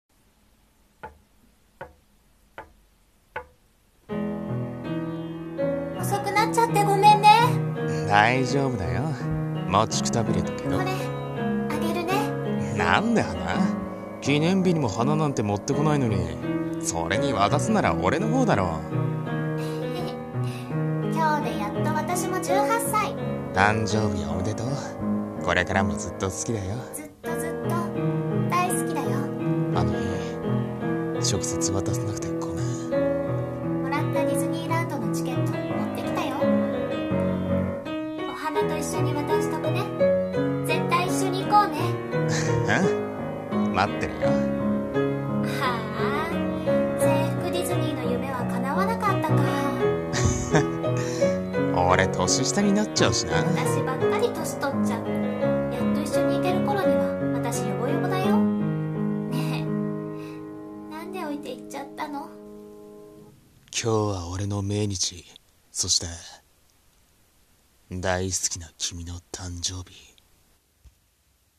声劇/キミの誕生日